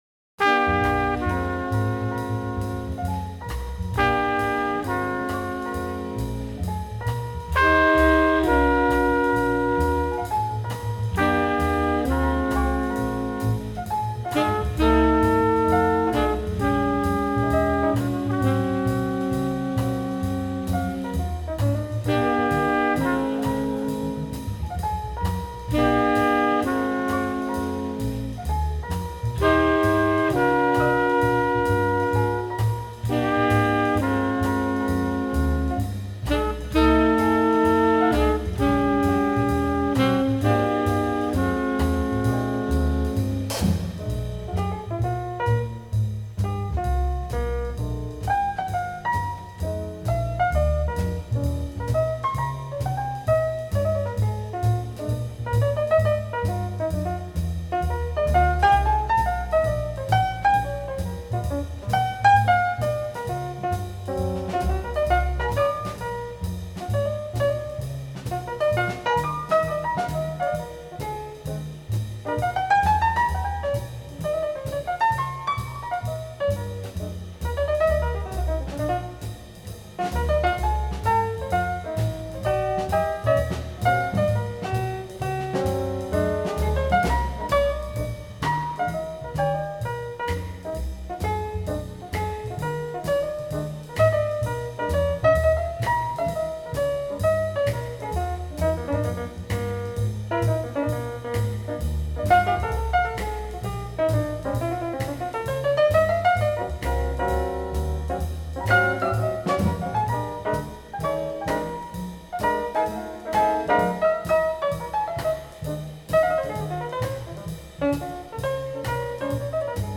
Jazz.